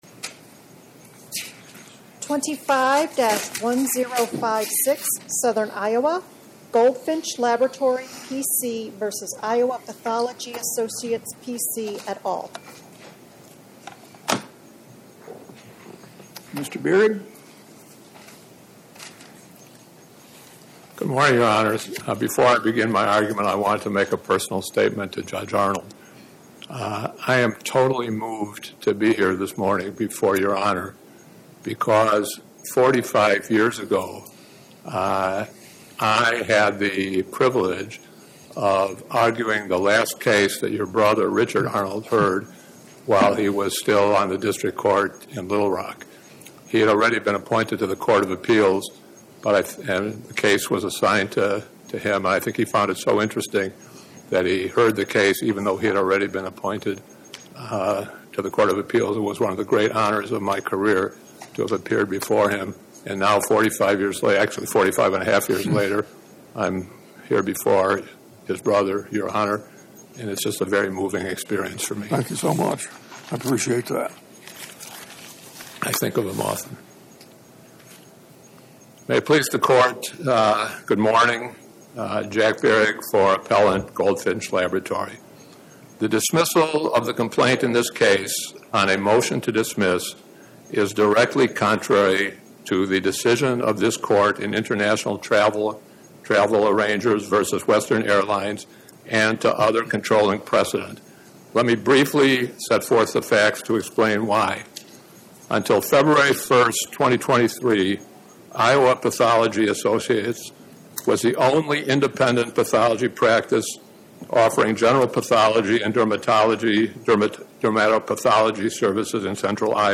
Oral argument argued before the Eighth Circuit U.S. Court of Appeals on or about 01/13/2026